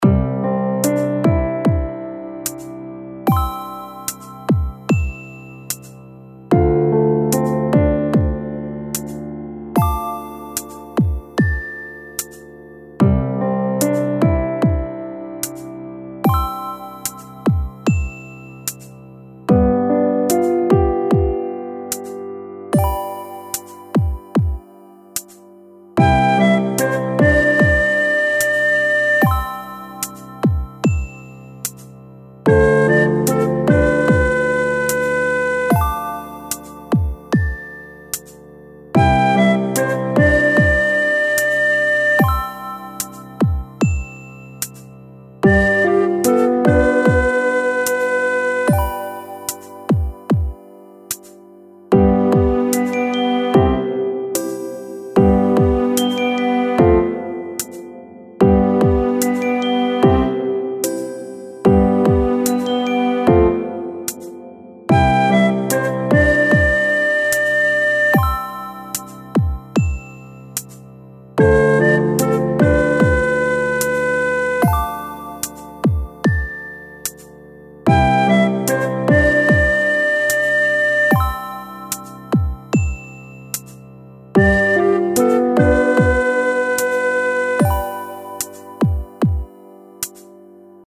のんびりした雰囲気を持った、みんなが眠りについた後の様な、夜の日常BGMです。
ほのぼのした雰囲気のコンテンツをイメージしています。